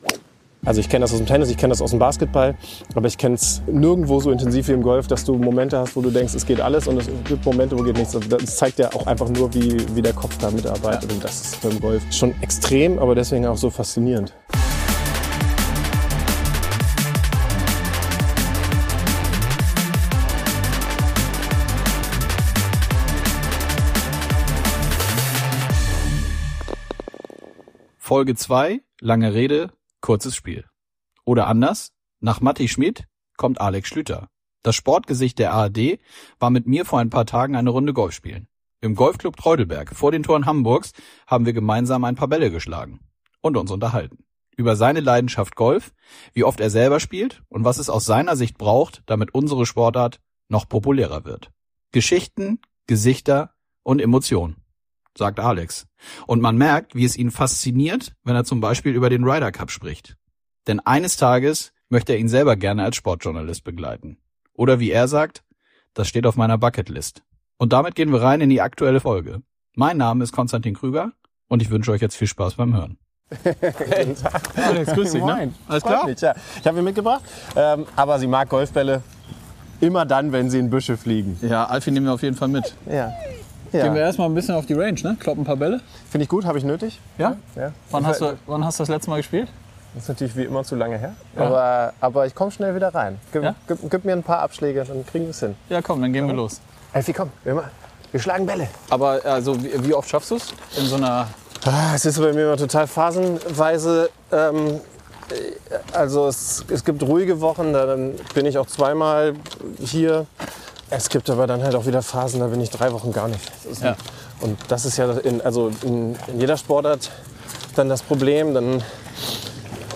Das "Sport-Gesicht" der ARD war mit uns vor ein paar Tagen eine Runde Golf spielen. Im Golfclub Treudelberg, vor den Toren Hamburgs, wurden ein paar Bälle geschlagen.
Und man merkt, wie es ihn fasziniert, wenn er zum Beispiel über den Ryder Cup spricht.